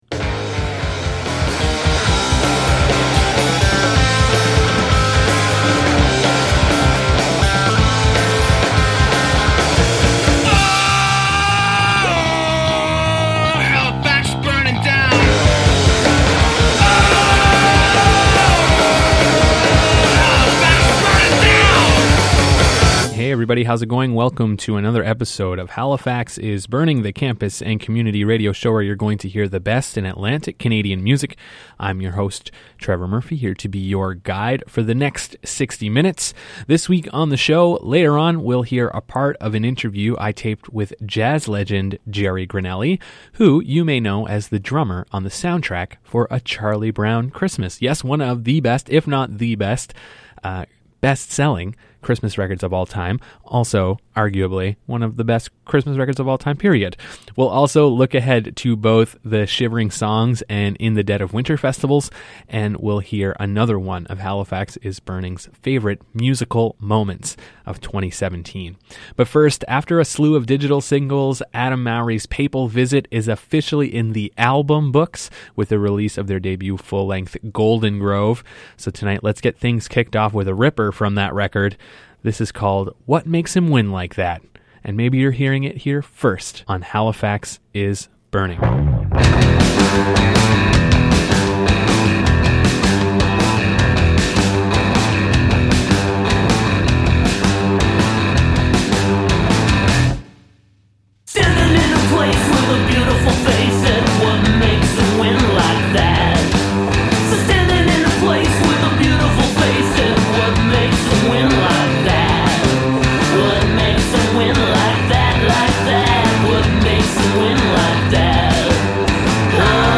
Featuring an interview w. jazz legend Jerry Granelli